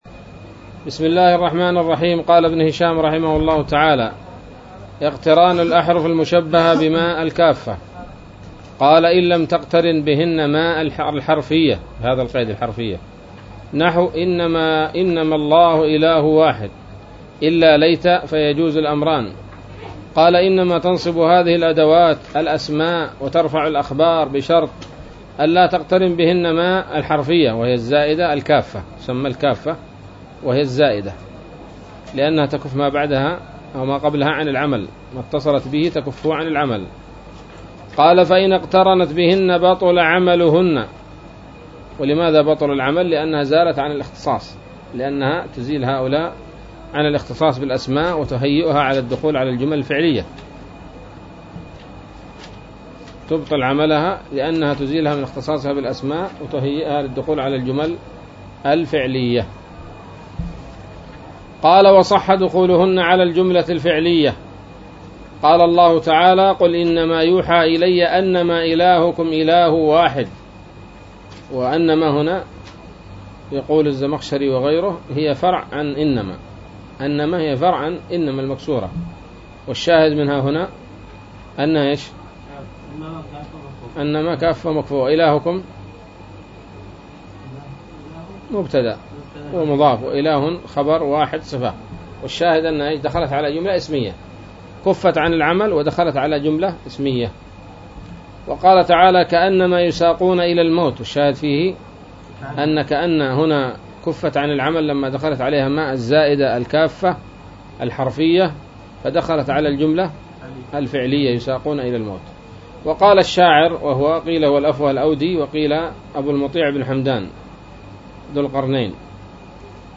الدرس الثالث والستون من شرح قطر الندى وبل الصدى